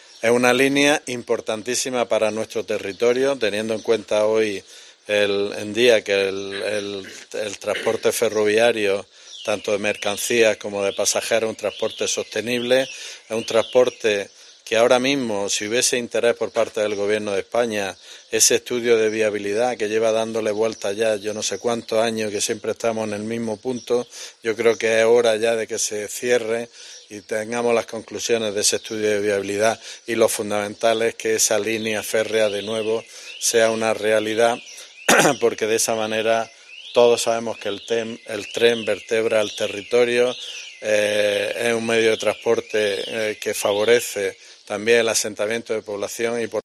Jesús Lorente, alcalde de Guadix